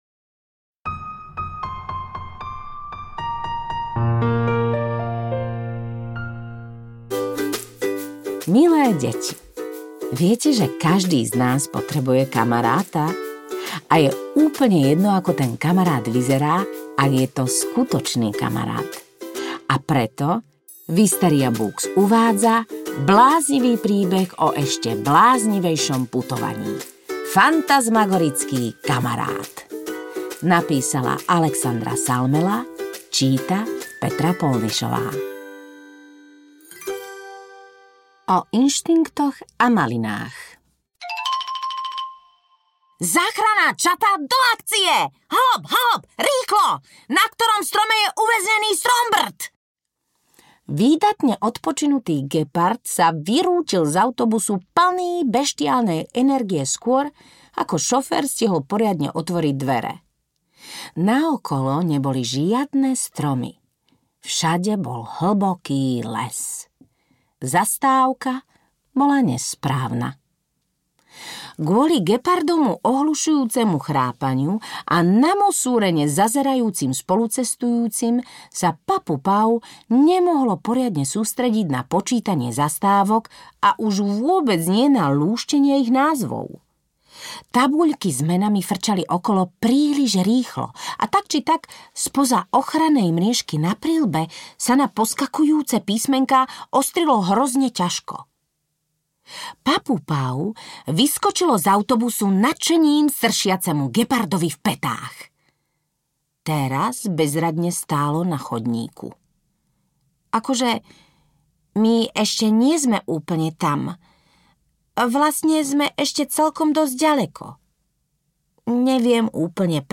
Fantazmagorický kamarát audiokniha
Ukázka z knihy
• InterpretPetra Polnišová